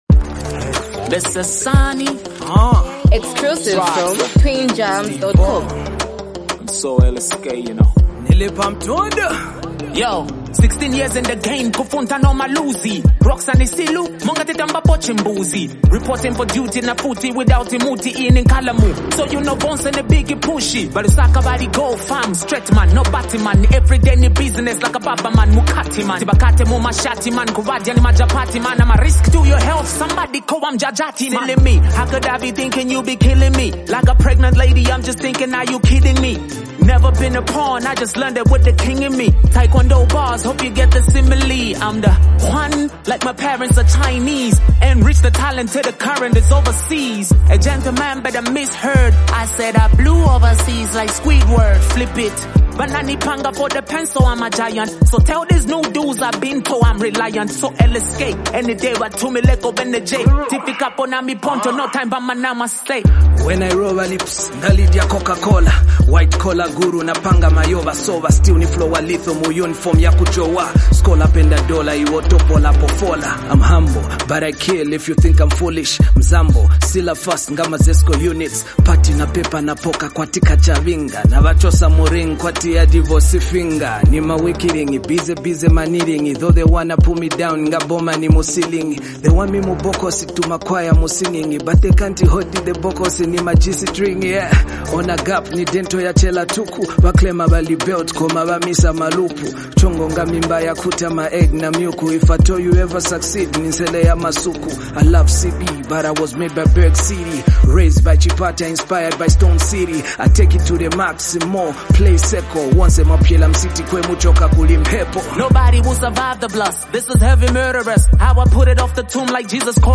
is a bold anthem